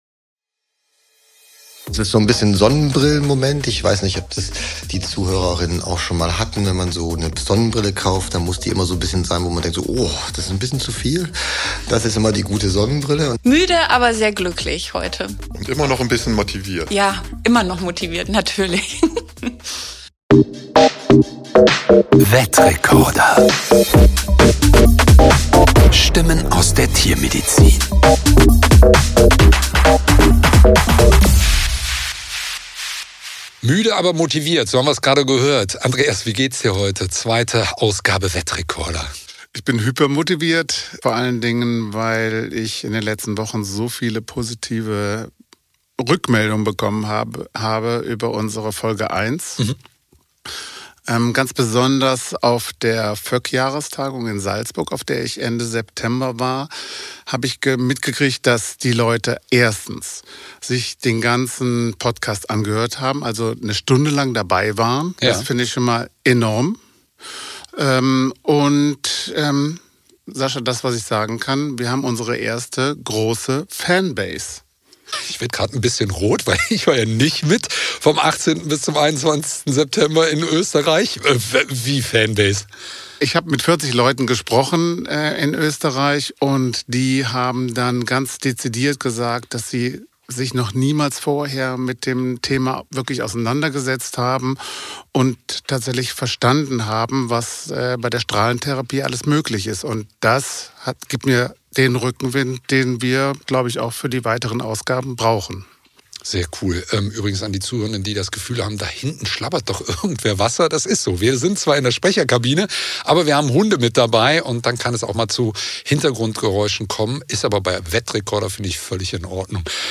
In dieser Folge sind wir mitten im Geschehen des ersten TiHo-VetFestivals an der Tierärztlichen Hochschule Hannover.
Dazu Stimmen von Studierenden, TFAs, Aussteller:innen und Besucher:innen.